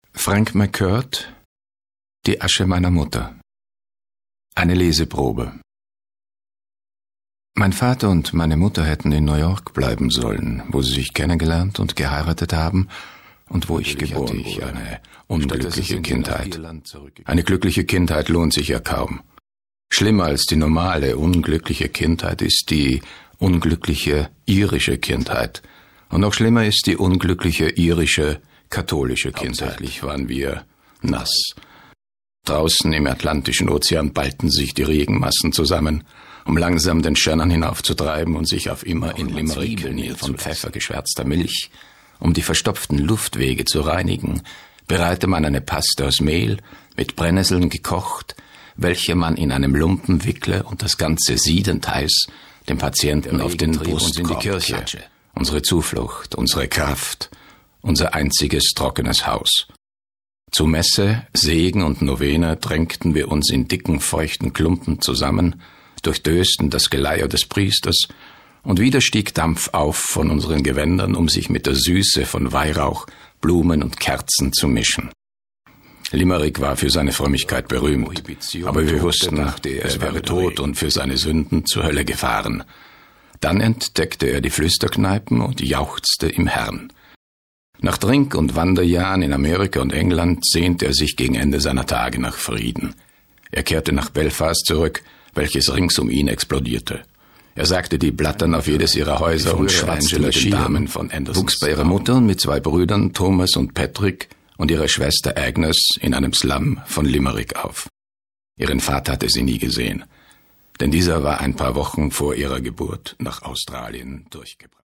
Leseprobe.mp3